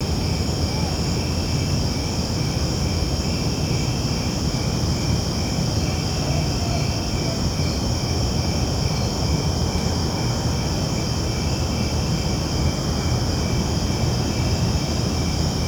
Index of /90_sSampleCDs/E-MU Producer Series Vol. 3 – Hollywood Sound Effects/Ambient Sounds/Night Ambience
NIGHT AMB02L.wav